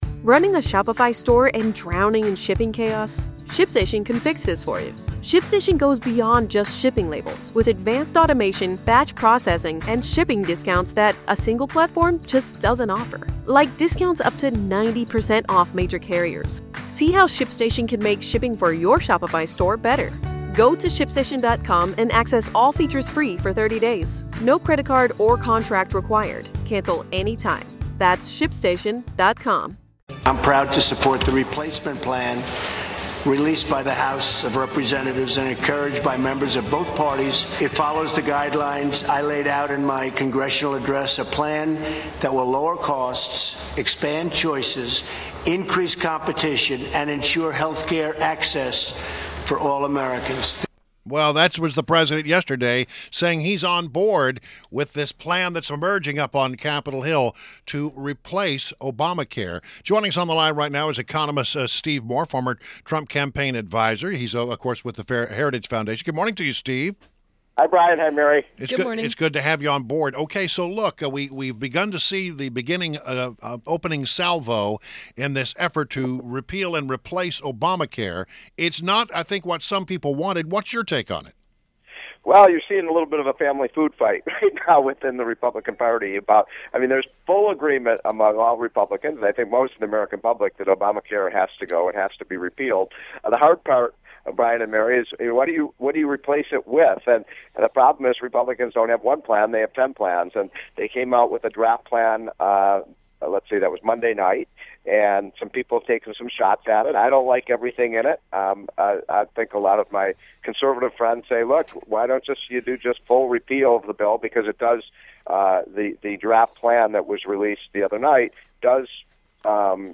INTERVIEW – STEVE MOORE – FORMER TRUMP CAMPAIGN ADVISER AND HERITAGE FOUNDATION ECONOMIST – analyzed the GOP’s replacement plan for ObamaCare.